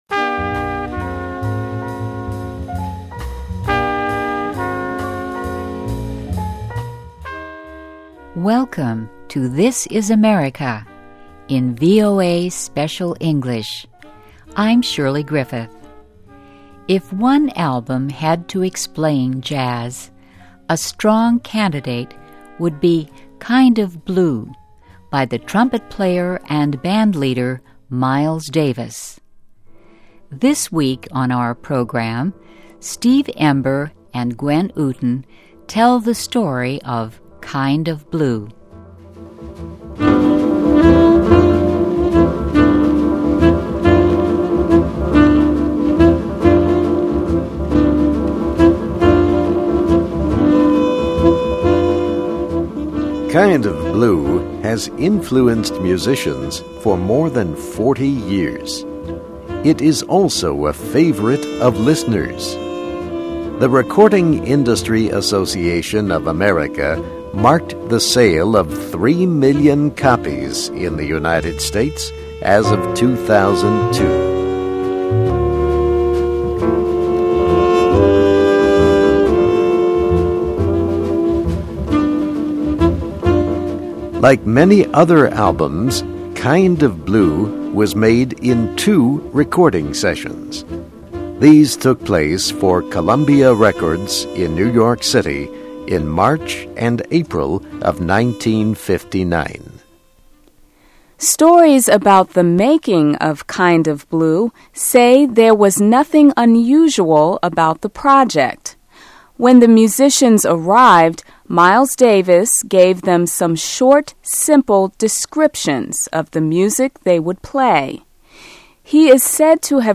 USA: The Making of One of Jazz's Most Influential Recordings (VOA Special English 2008-11-17)<meta name="description" content="Text and MP3 File.
Listen and Read Along - Text with Audio - For ESL Students - For Learning English